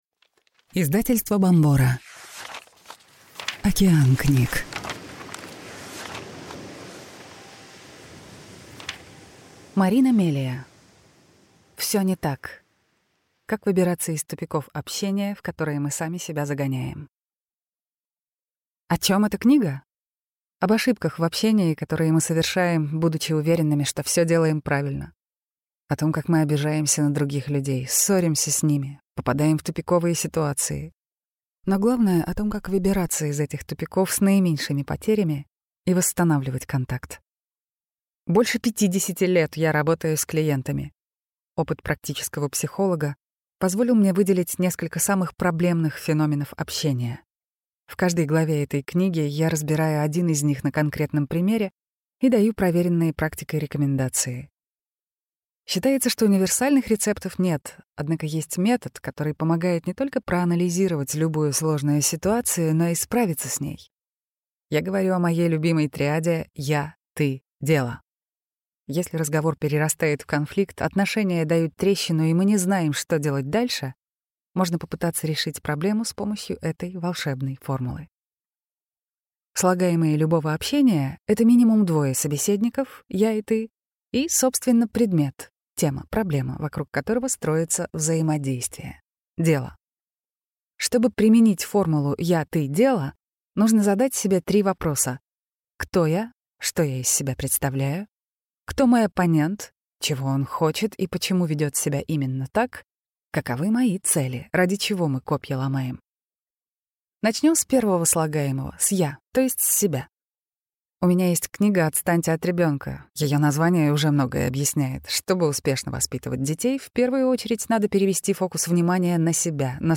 Аудиокнига Всё не так. Как выбираться из тупиков общения, в которые мы сами себя загоняем | Библиотека аудиокниг